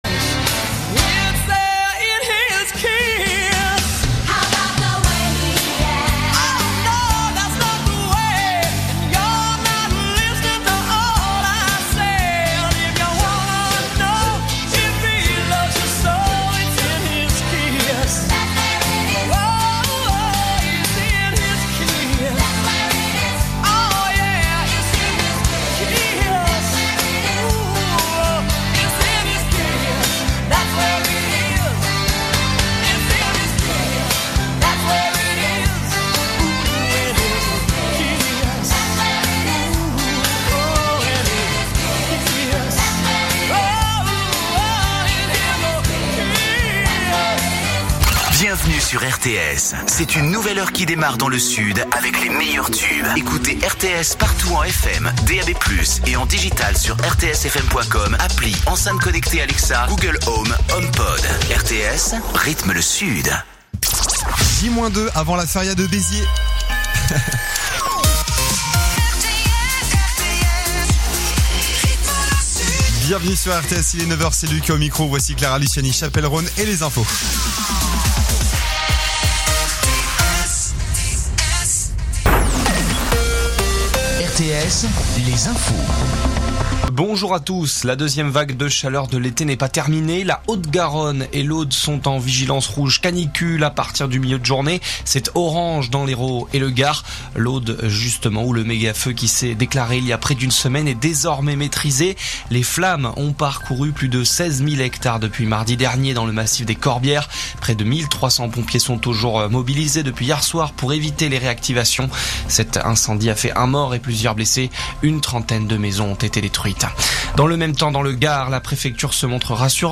info_narbonne_toulouse_472.mp3